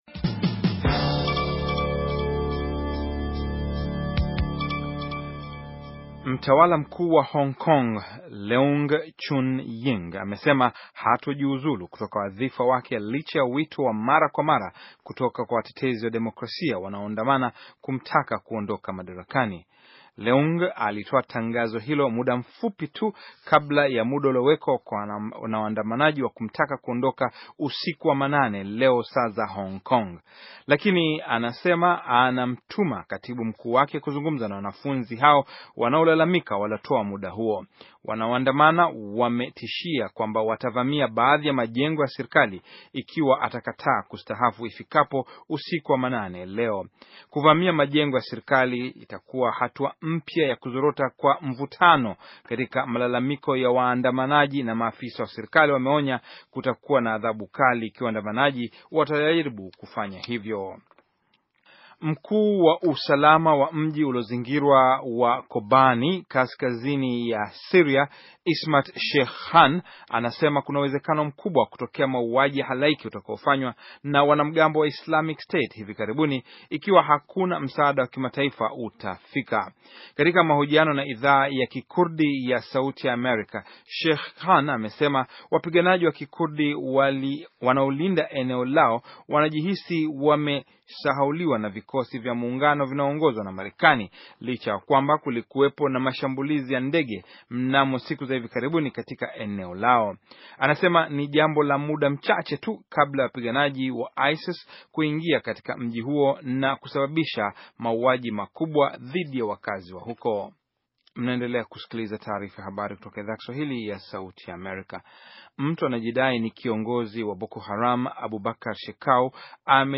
Taarifa ya habari - 5:48